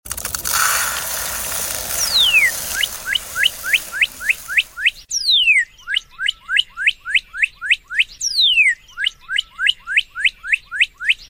✨Cardinal’s call . . . sound effects free download